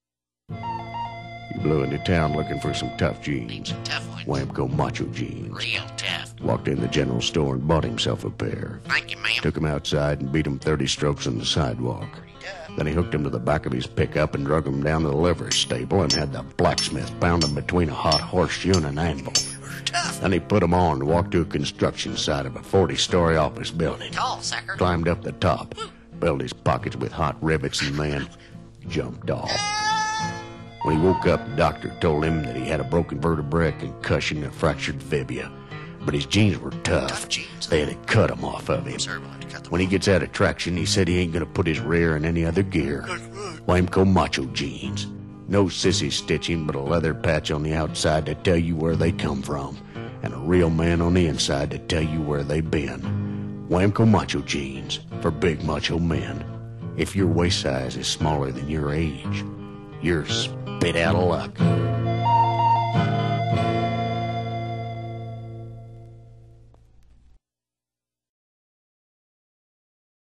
He has used the studio recording equipment and software at KONA to dub the LP tracks sans clicks and pops!.